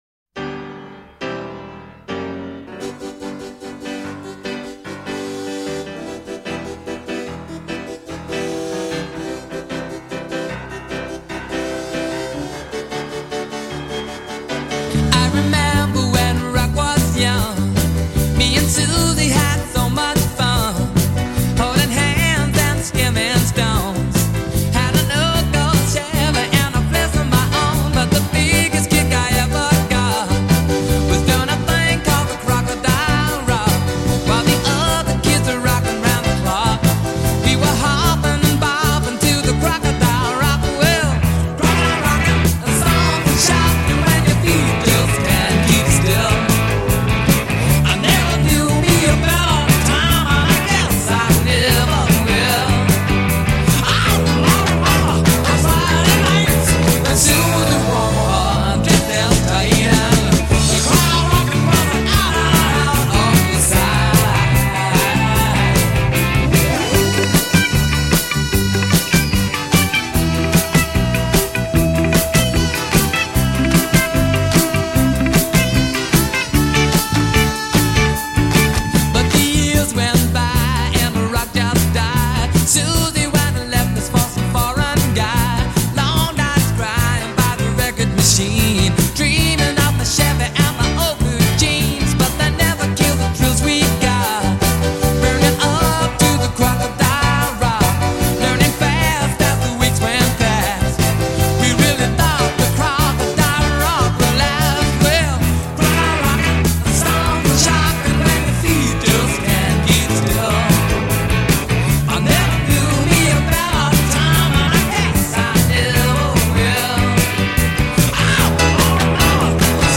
И музыка в стиле!!!:))